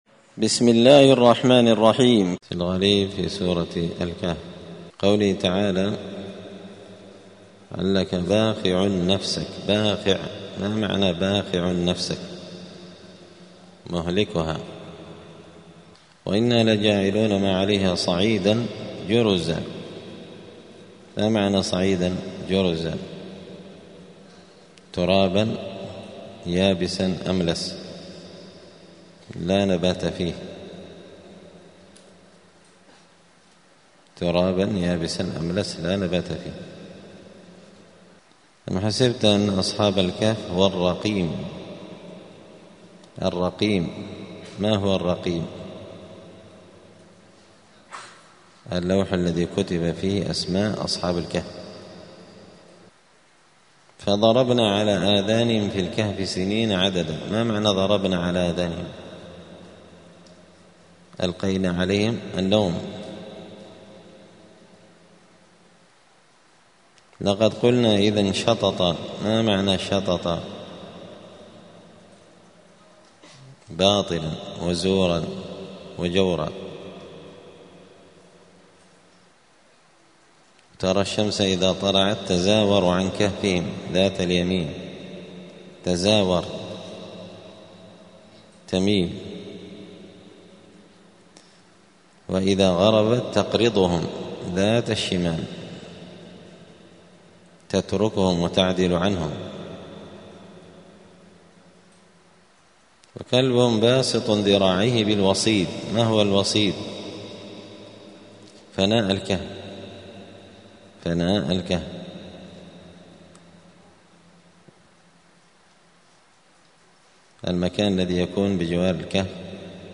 مذاكرة لغريب القرآن في رمضان – الدرس السابع عشر (17) : غريب الجزء السادس عشر.
دار الحديث السلفية بمسجد الفرقان قشن المهرة اليمن